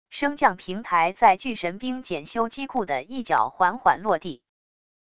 升降平台在巨神兵检修机库的一角缓缓落地wav音频